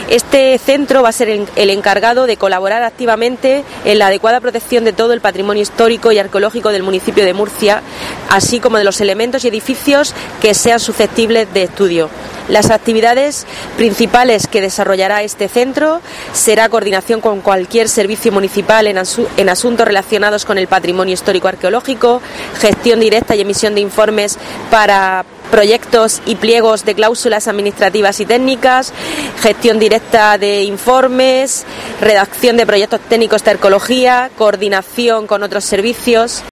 Paqui Pérez, portavoz del ayuntamiento de Murcia